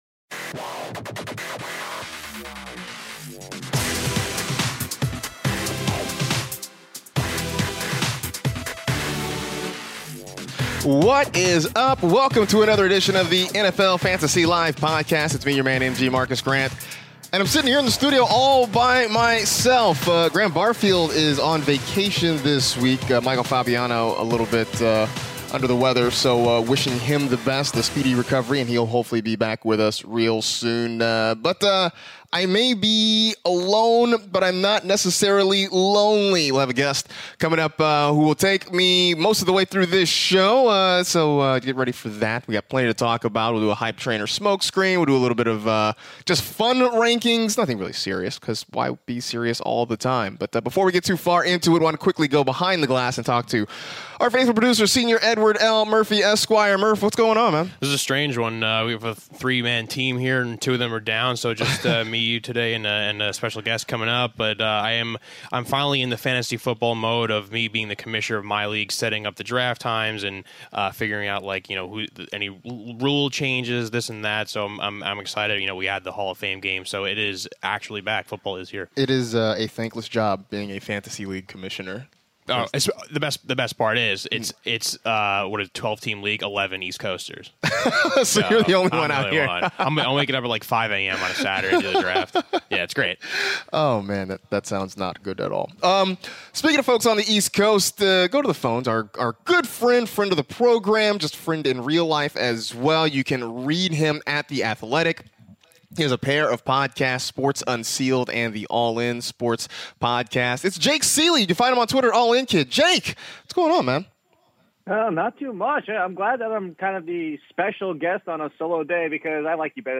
solo in studio
phones in for the entire show!